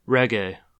Reggae (/ˈrɛɡ/
En-us-reggae.ogg.mp3